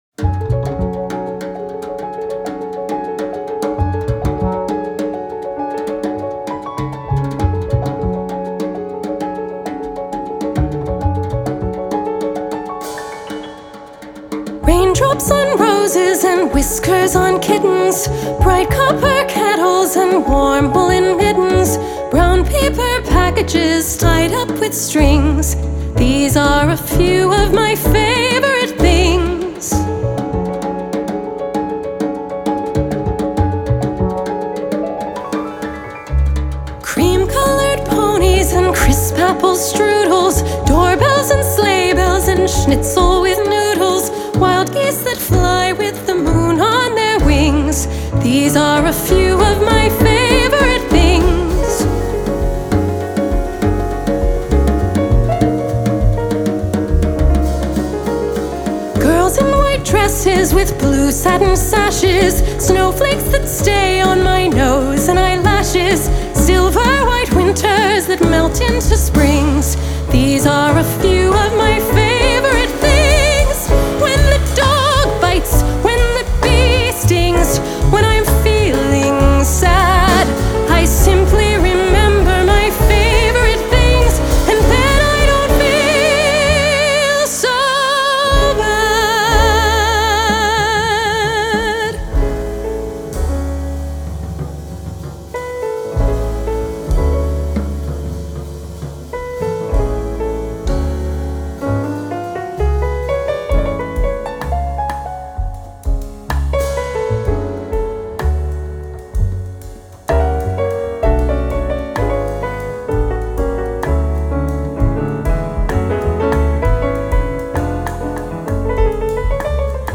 Crossover soprano